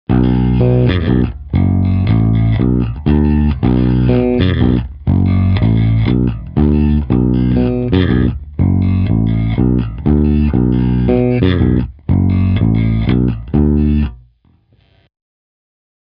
Je to pořízené přes iPad v GarageBandu přes Clean Combo.
Oba snímače